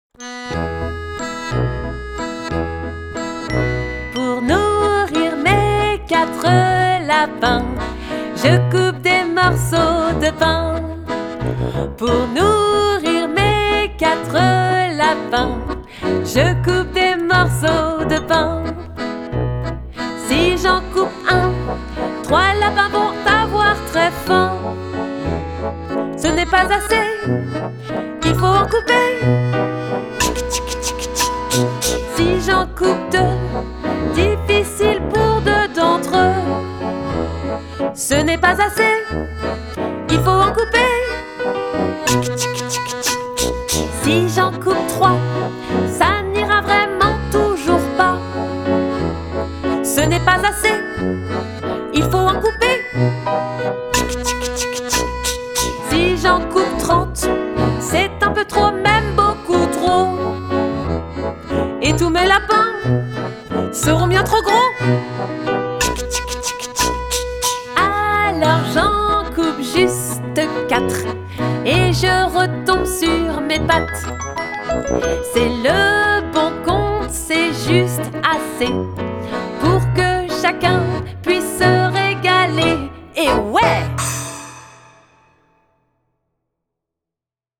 Comptines mathématiques
piano, accordéon